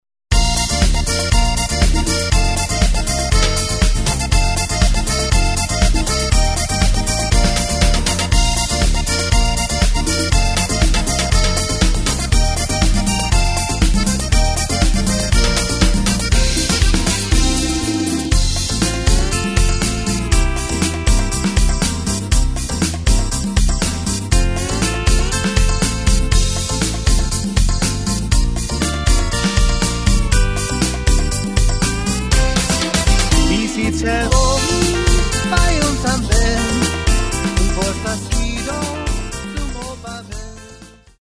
Tempo:         120.00
Tonart:            Bb
Party-Schlager aus dem Jahr 2021!